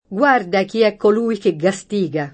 gU#rda ki $ kkol2i ke ggaSt&ga!] (Manzoni) — stessa alternanza nel part. pass.